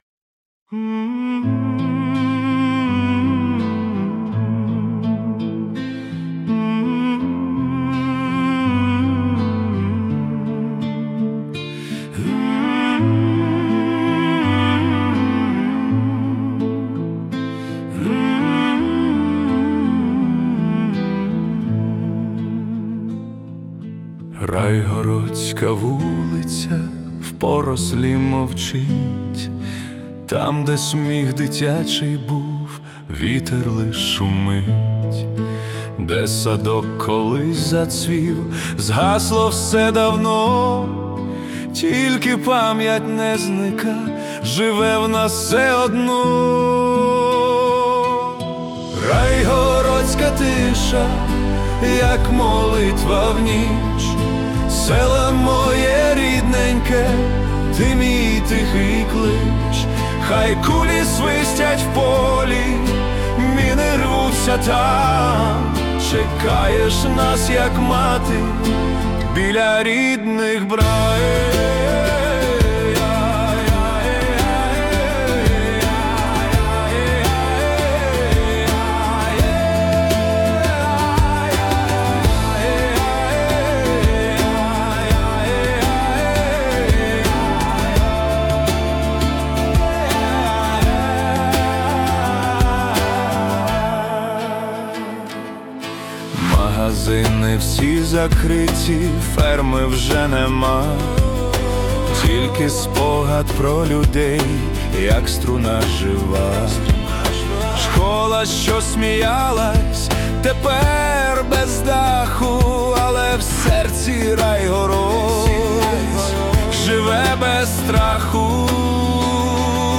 Epic Synth-Pop / Italo Disco
це емоційно насичений трек у стилі Epic Synth-Pop (118 BPM).